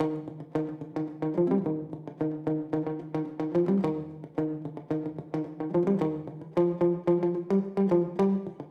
13 Second Guitar PT 1-4.wav